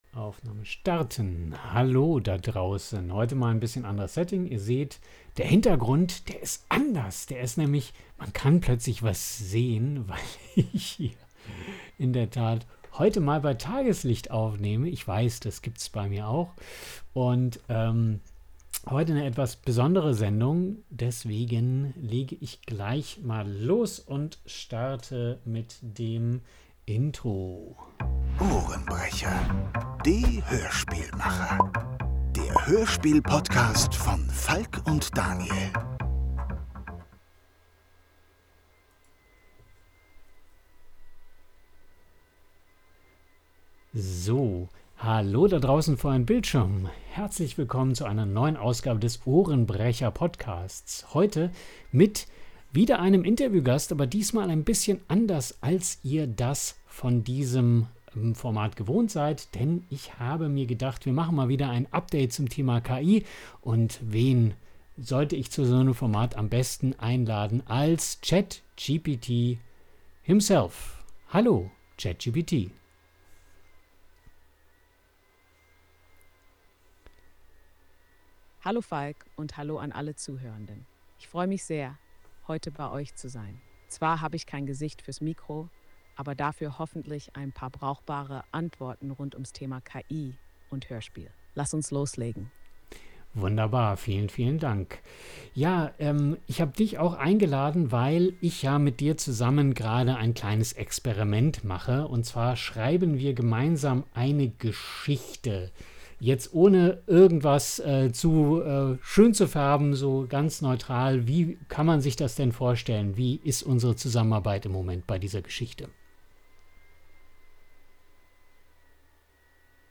Ein offenes, ehrliches und überraschend persönliches Gespräch über kreatives Schreiben mit KI, Kontrolle, Vertrauen und die Zukunft des Erzählens – mitten zwischen Vision und Verantwortung.